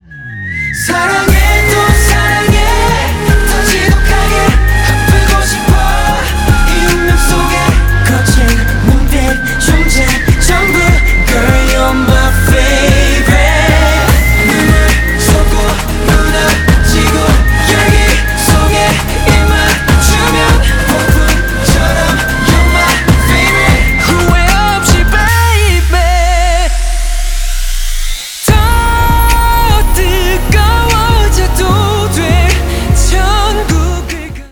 Жанр: K-pop